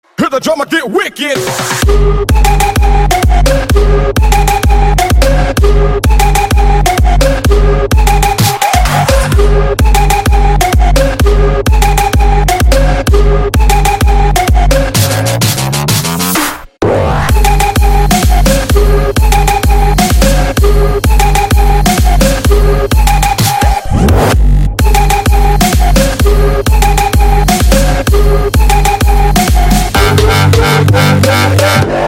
• Качество: 320, Stereo
Electronic
быстрые
electro house